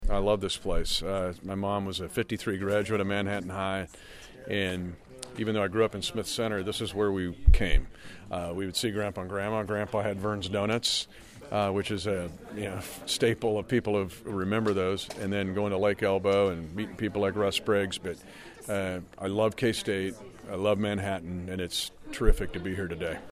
Holthus, who last spoke at this event in 2003 says Manhattan is like a second home for him.